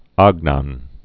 (ägnôn), Shmuel Yosef 1888-1970.